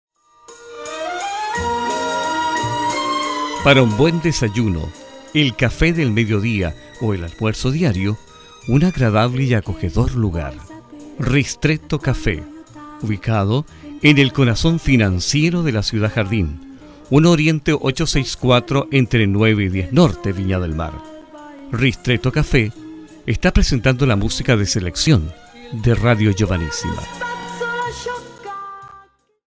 Soy un Locutor profesional chileno,de vasta trayecto ria en Emisoras de mi pais.
Sprechprobe: Industrie (Muttersprache):